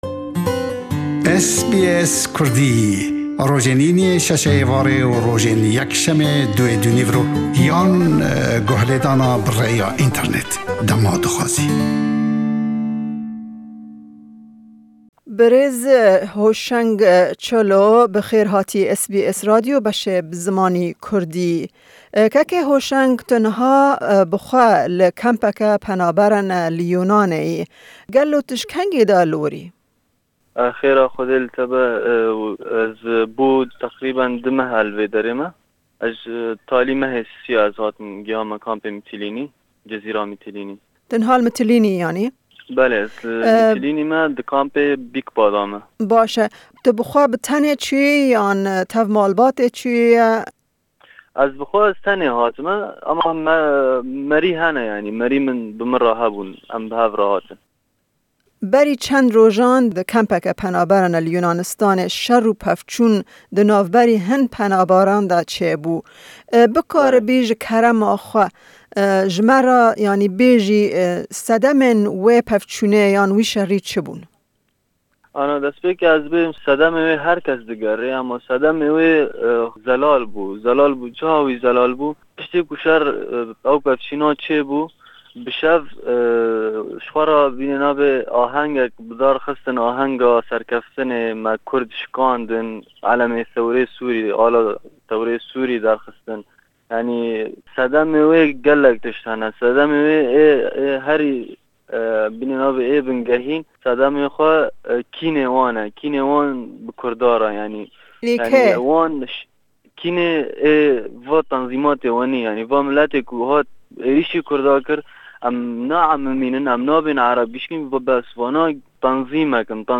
Me derbarê wê pirsgirêkê hevpeyvînek